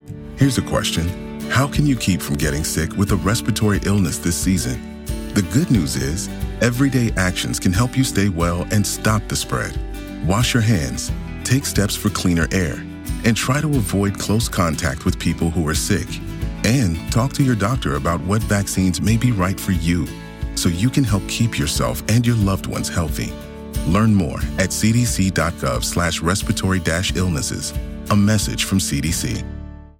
Public service announcements (PSA)